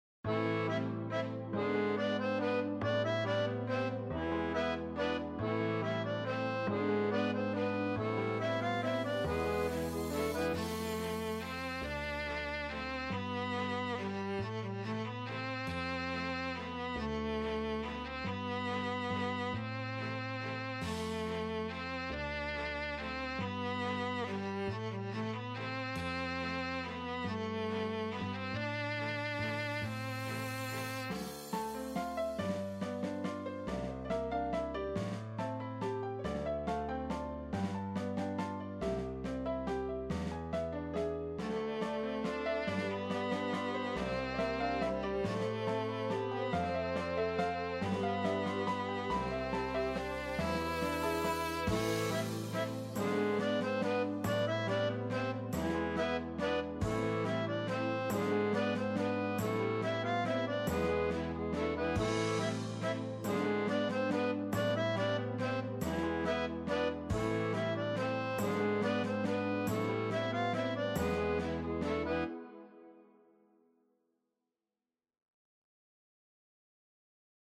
BGM
カントリー暗い